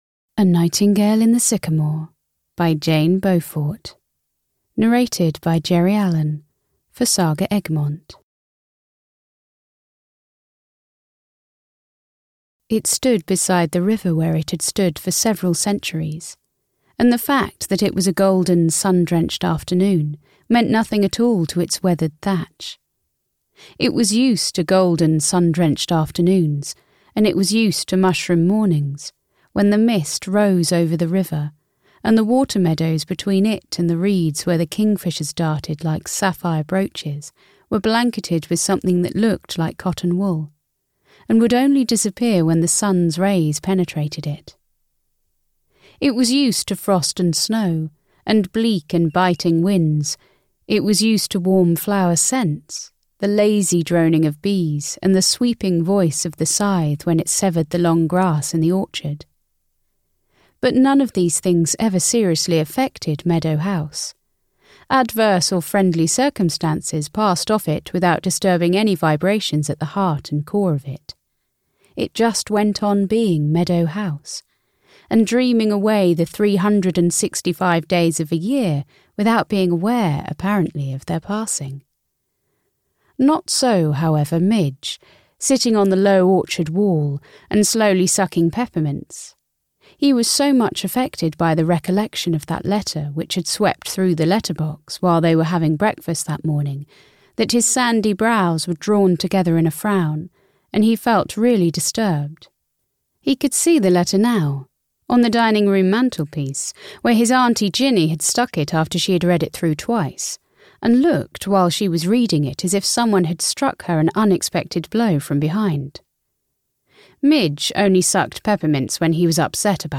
A Nightingale in the Sycamore (EN) audiokniha
Ukázka z knihy